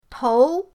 tou2.mp3